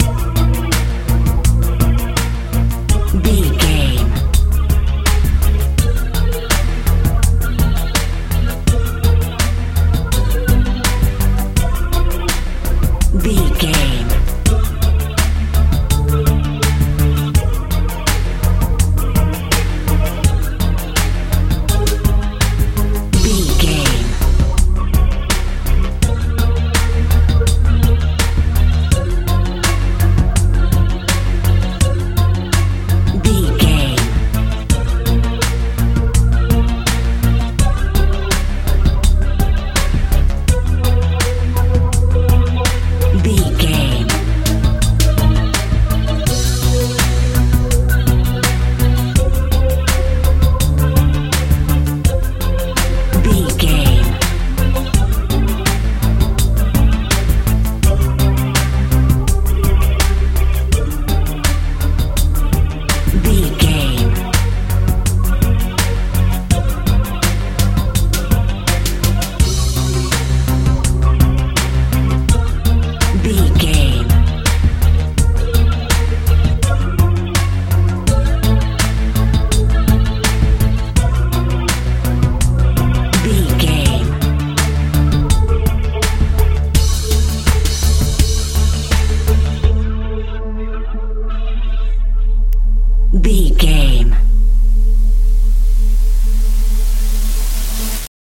modern dance feel
Ionian/Major
C♯
strange
haunting
synthesiser
bass guitar
drums
suspense
disturbing